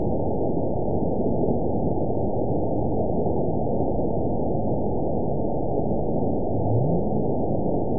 event 911317 date 02/22/22 time 09:45:45 GMT (3 years, 3 months ago) score 9.37 location TSS-AB01 detected by nrw target species NRW annotations +NRW Spectrogram: Frequency (kHz) vs. Time (s) audio not available .wav